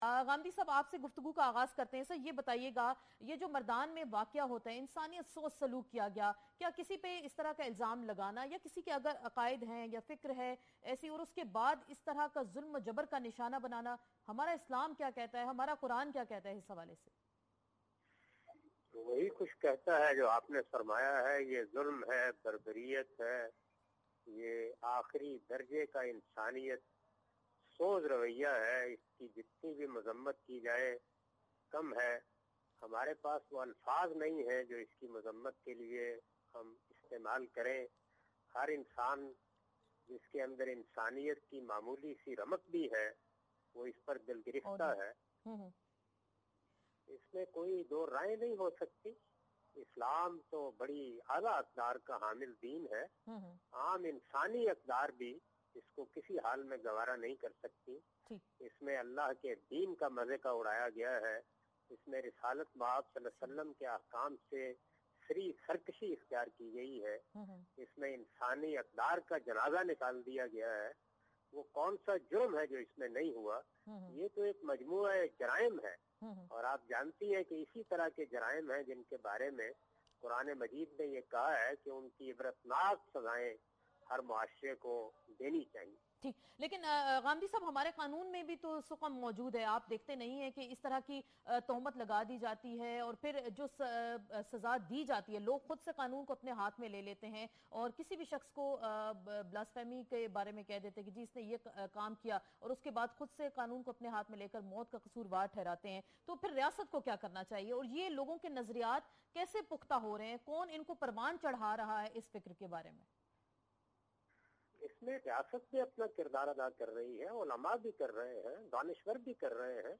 Category: TV Programs / Neo News /